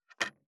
590魚切る,肉切りナイフ,まな板の上,包丁,ナイフ,
効果音